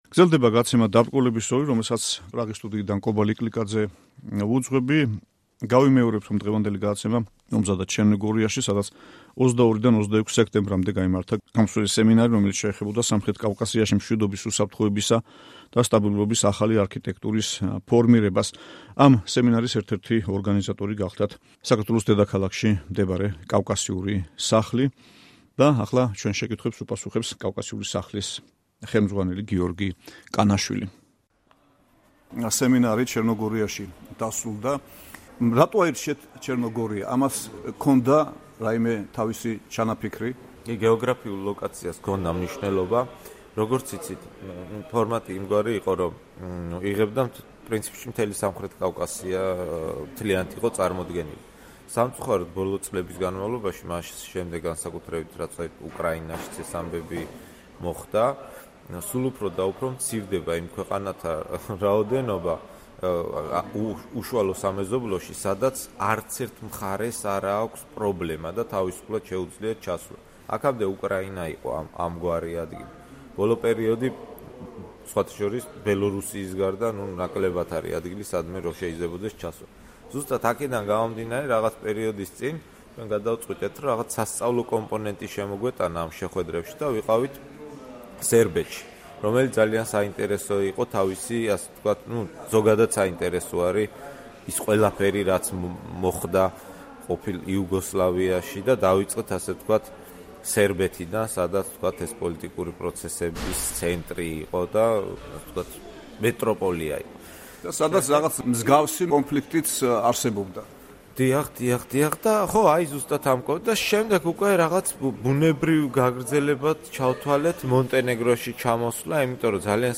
საუბარი